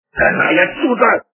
» Звуки » Люди фразы » Из к/ф - Канай отсюда!
При прослушивании Из к/ф - Канай отсюда! качество понижено и присутствуют гудки.